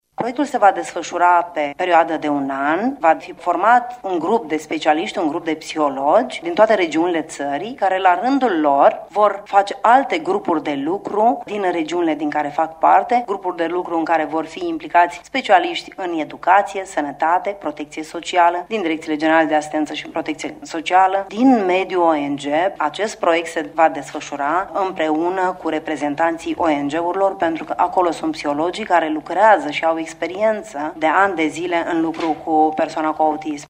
Iniţiativa de a aduce acest proiect în ţara noastră îi aparţine deputatului PSD, Cristina Nichita, care ne-a explicat cum va funcţiona: